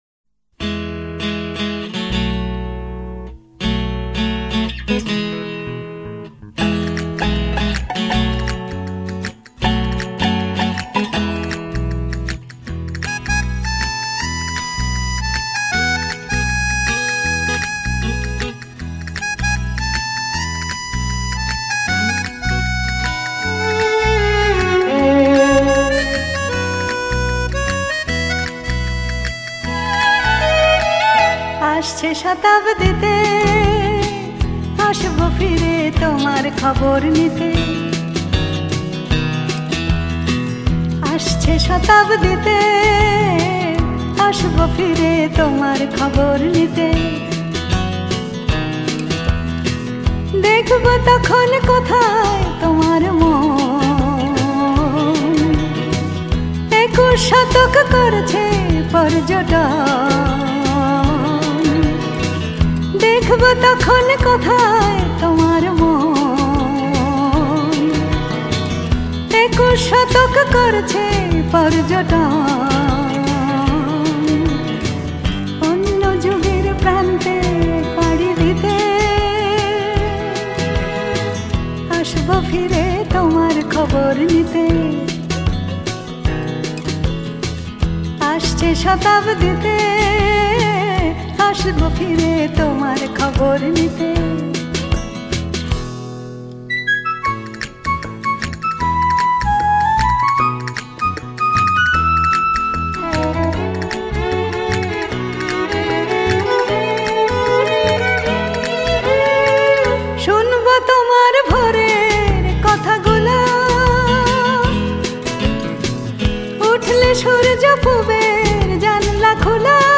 06asche-shatabditelive.mp3